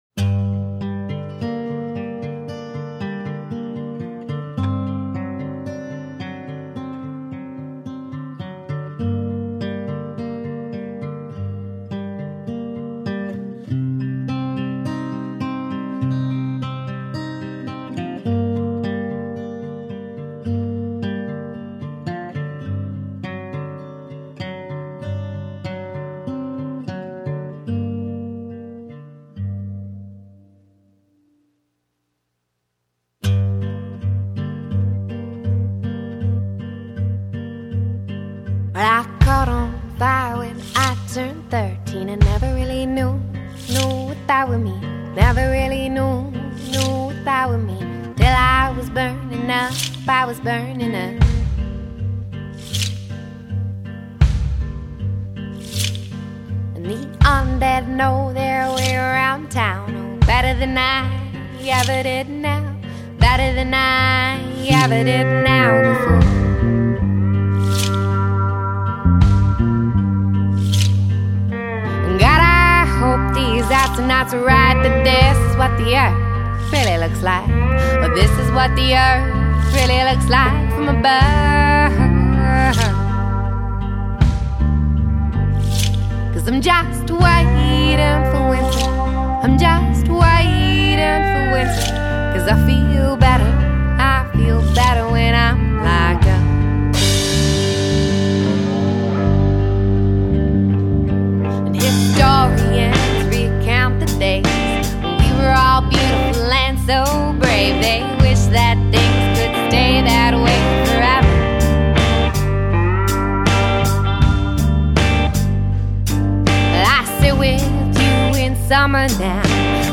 Vocals/Guitar
Drums
Keys/Multi-Instruments
Bass
sultry vocals are front and center